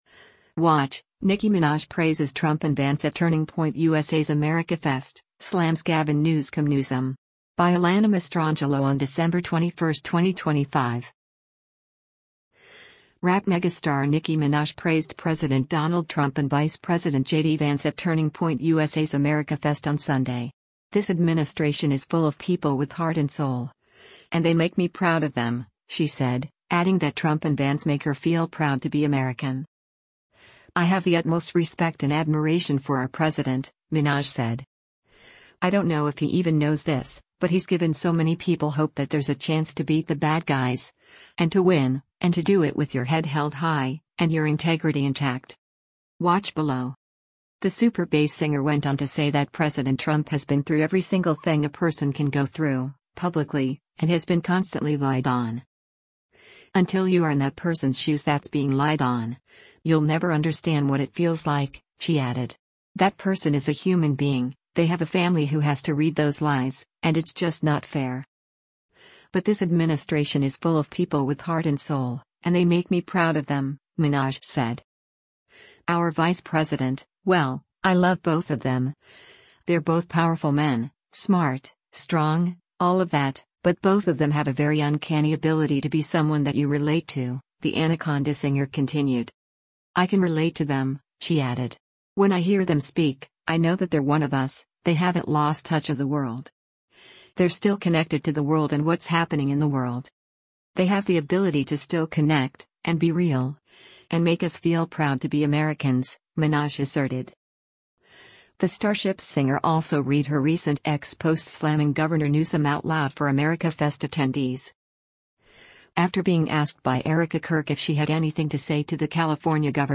PHOENIX, ARIZONA - DECEMBER 21: Erika Kirk interviews surprise guest Nicki Minaj on the fi
The “Starships” singer also read her recent X posts slamming Governor Newsom out loud for AmericaFest attendees.